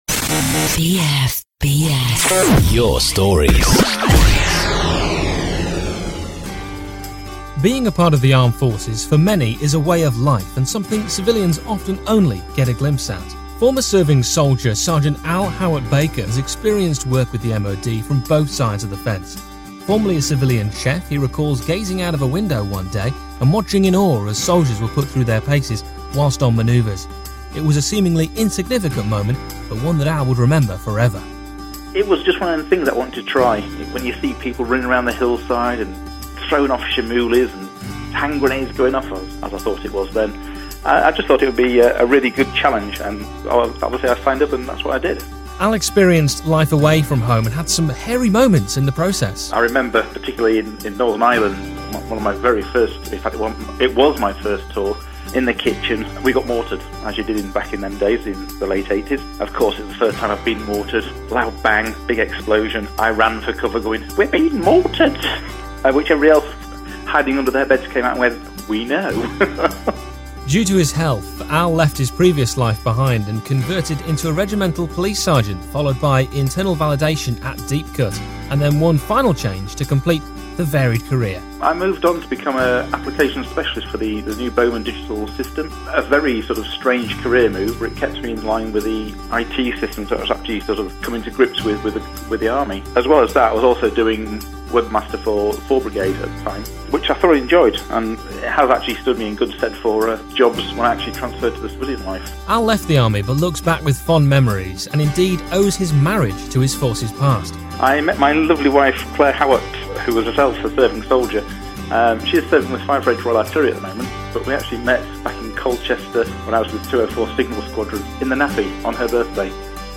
a former serving soldier talks about how he became a military chef.